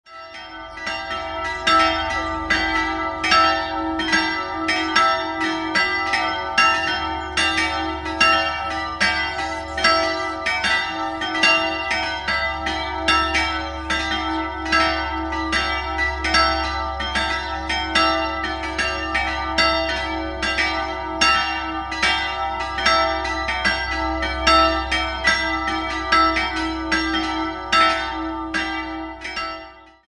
3-stimmiges Geläute: e''-fis''-ais''